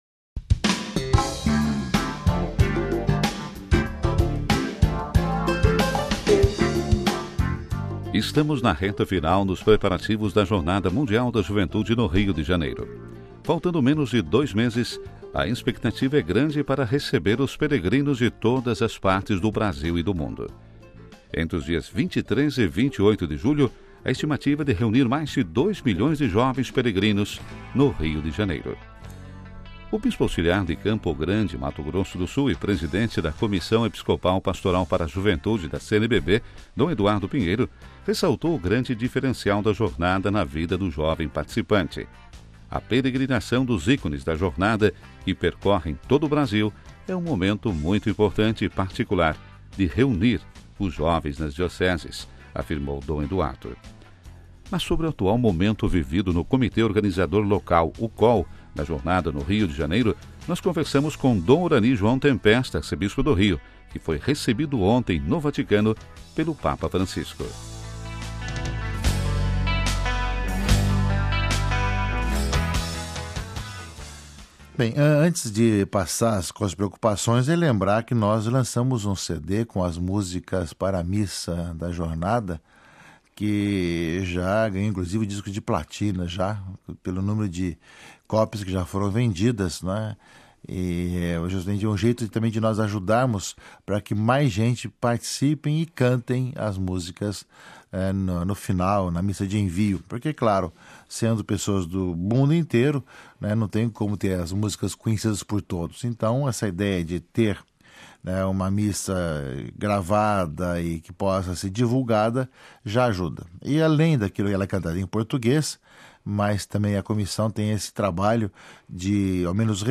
Sobre o atual momento vivido no Comitê Organizador Local, COL, da Jornada no Rio de Janeiro, nós conversamos com Dom Orani João Tempesta, Arcebispo do Rio, que foi recebido ontem, no Vaticano pelo Papa Francisco.